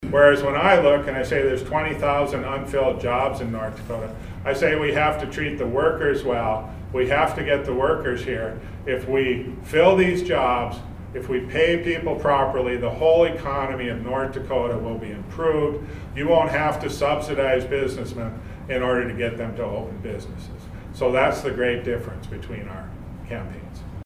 Gubernatorial Candidate Marvin Nelson was part of the press conference at the Gladstone Inn & Suites and opened with the fact that North Dakota was already facing a huge shortfall in the state budget.